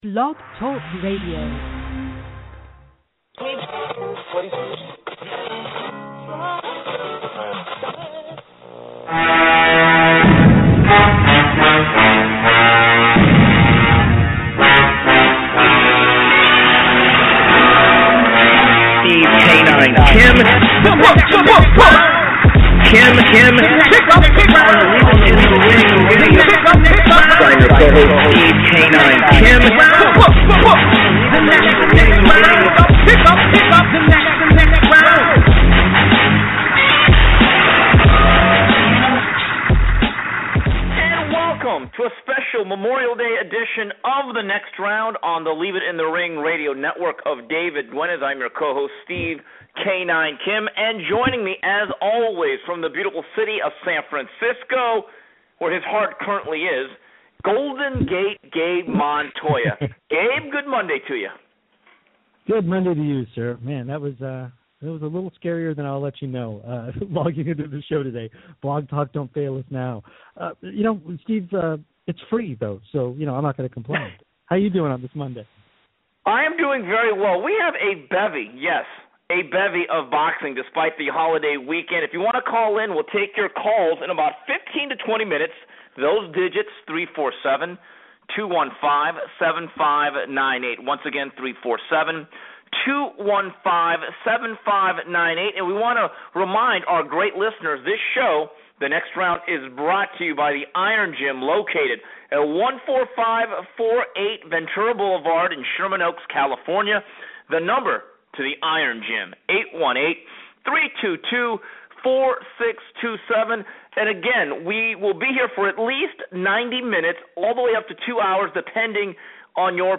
Plus they breakdown Amir Khan vs Chris Algieri and Bryan Vasquez vs Javier Fortuna. And as always, news, notes and your calls.